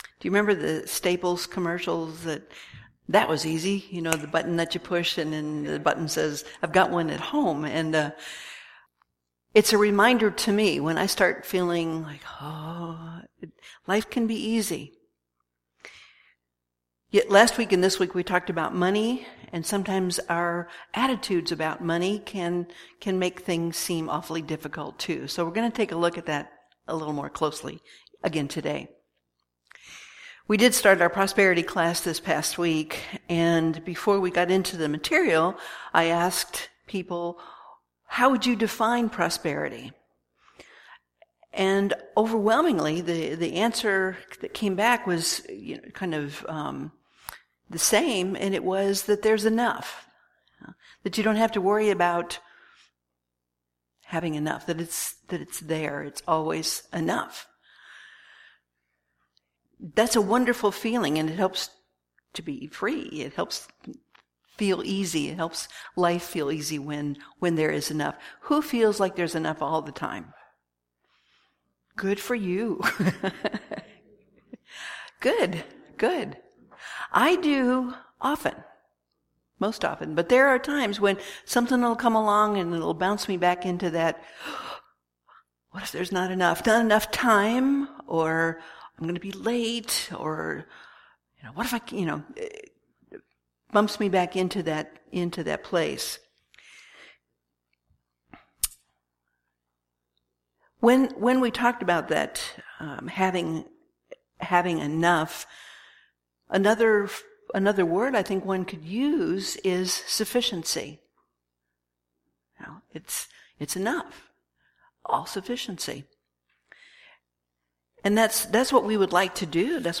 Series: Sermons 2015